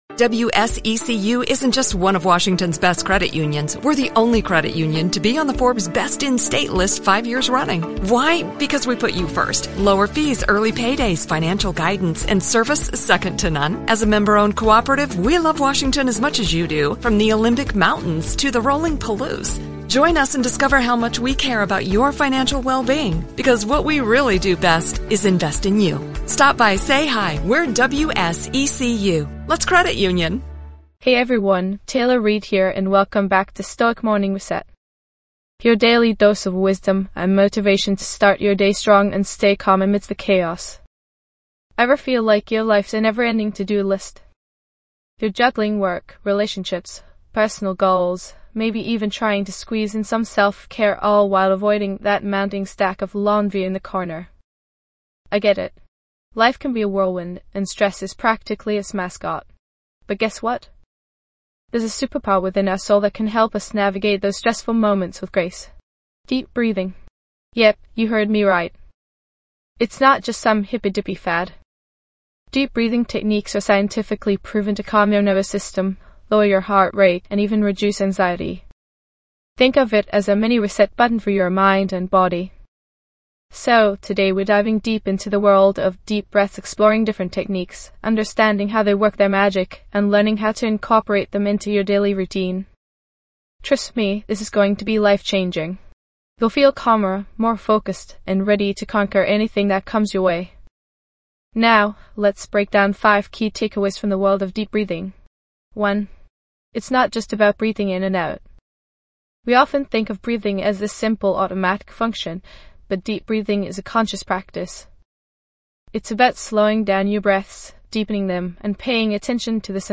- Experience guided deep breathing techniques for instant relaxation.
This podcast is created with the help of advanced AI to deliver thoughtful affirmations and positive messages just for you.